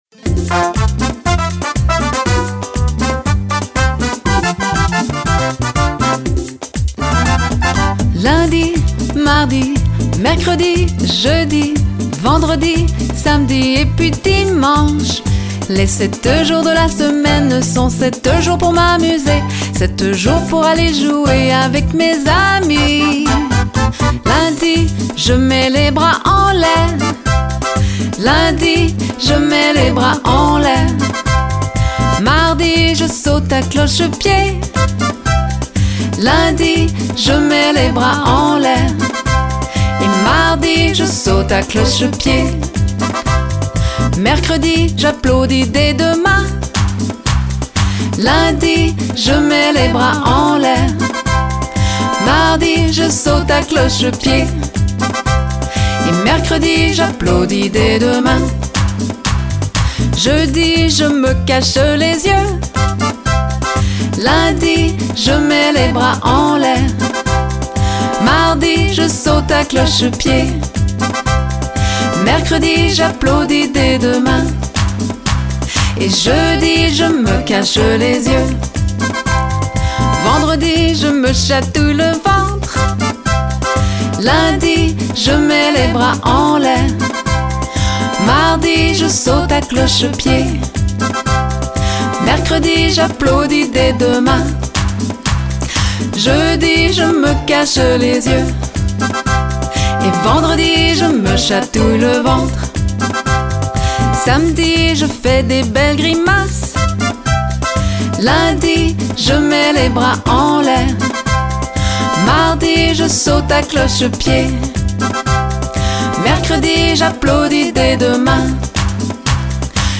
une chanson amusante, orientée vers l’action